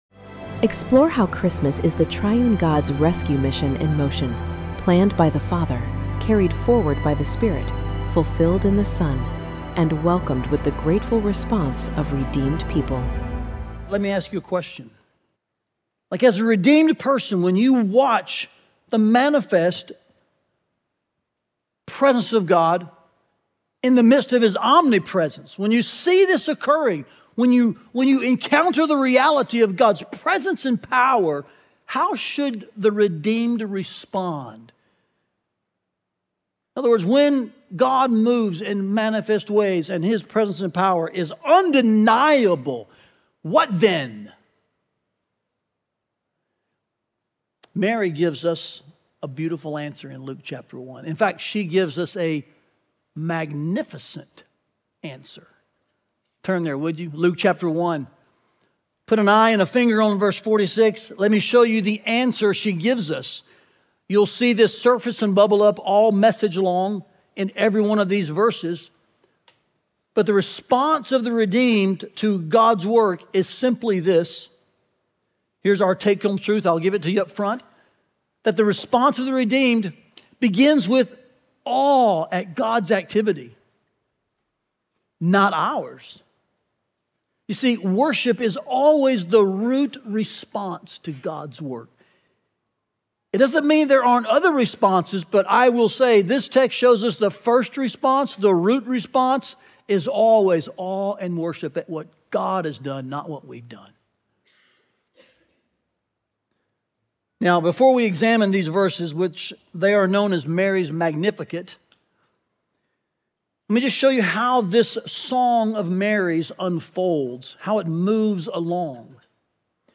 Listen to the fourth sermon from Advent 2025 and learn more about our Advent series here.